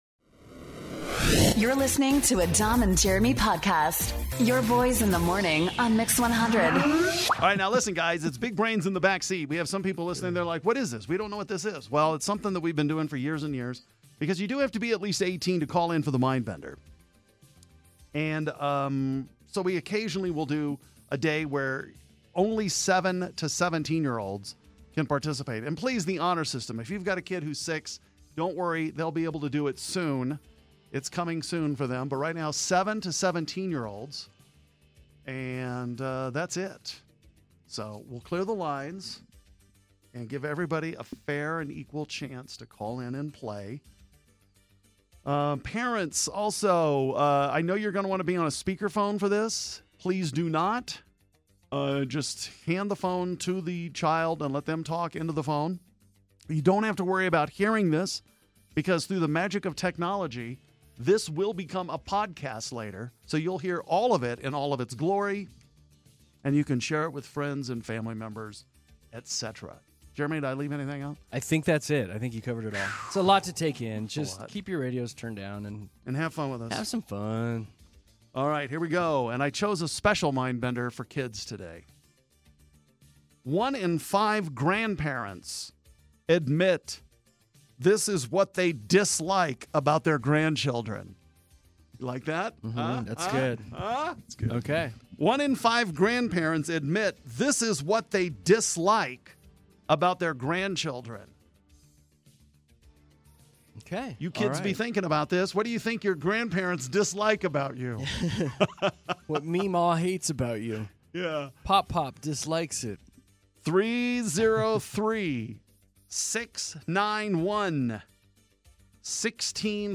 Thank you to everyone who called in for Big Brains in the Backseat!